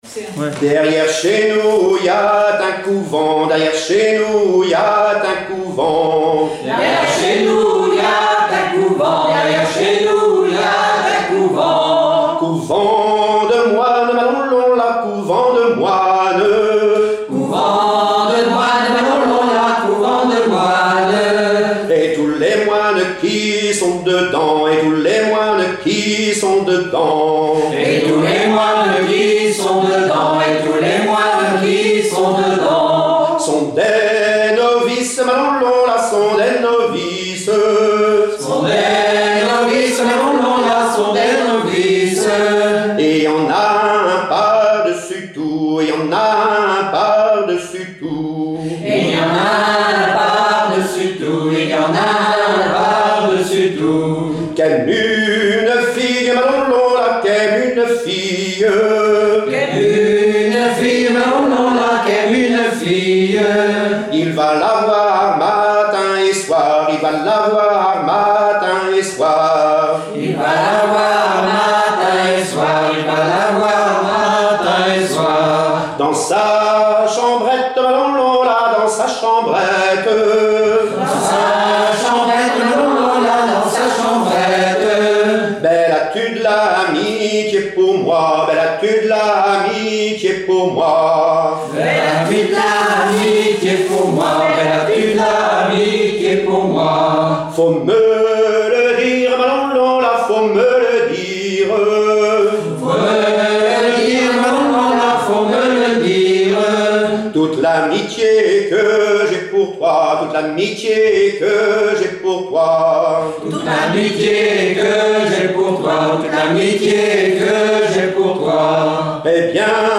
danse : ronde : rond de l'Île d'Yeu
Pièce musicale inédite